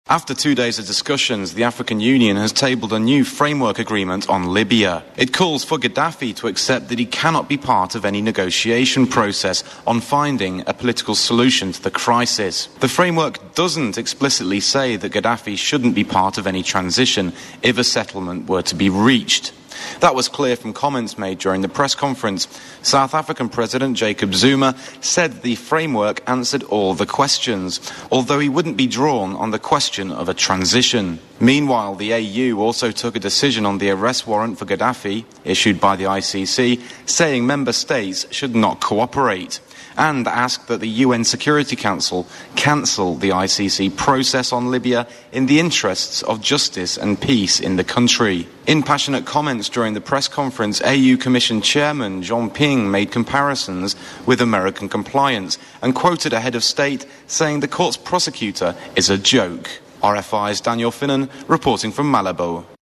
Report: Framework agreement on Libya, 2nd July 2011